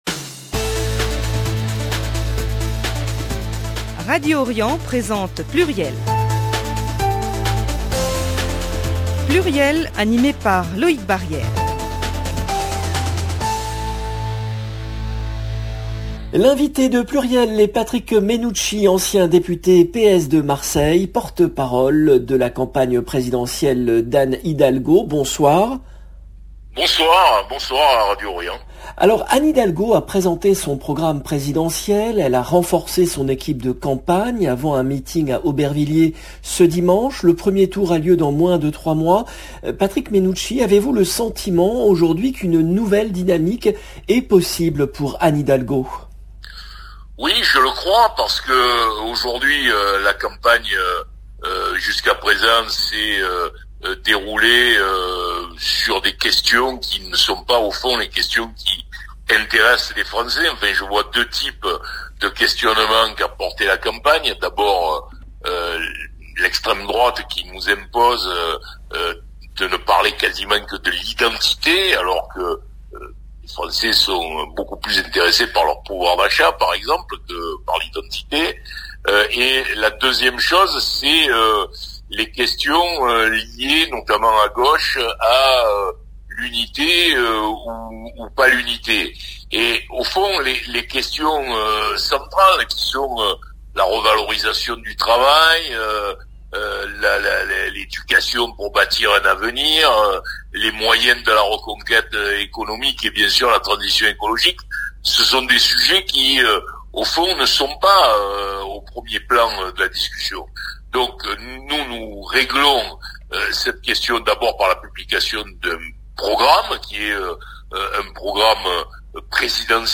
L’invité de PLURIEL est Patrick Mennucci , ancien député socialiste de Marseille, porte-parole de la campagne présidentielle d’Anne Hidalgo